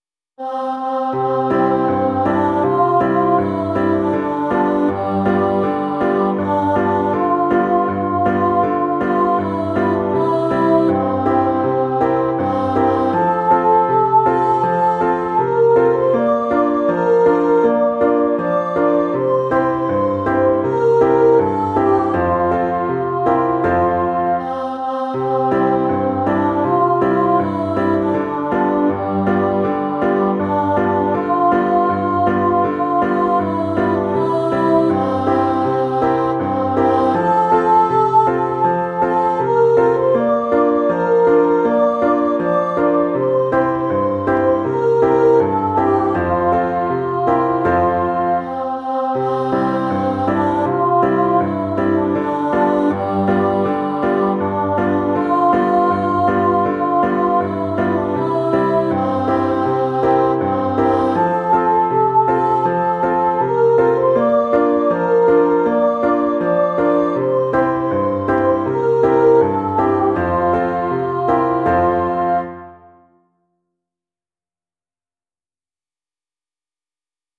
Each Song has a synthesised recording for listening.